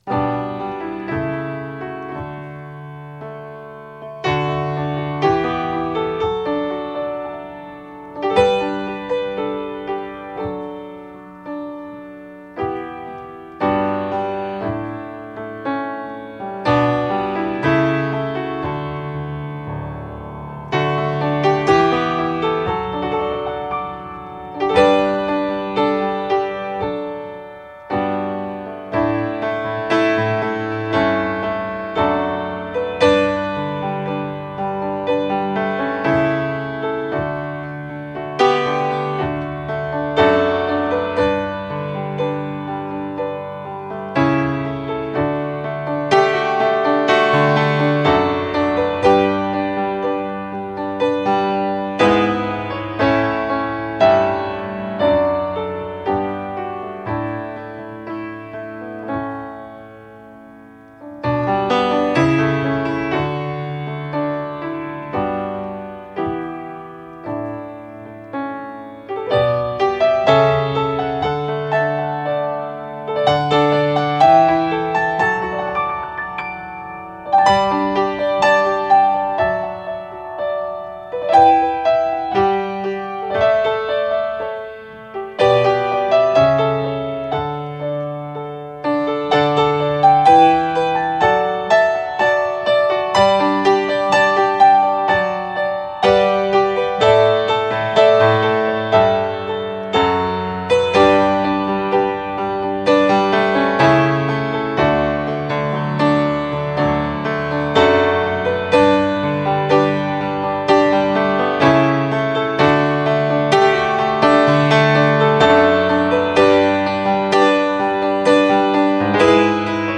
Hymns on Piano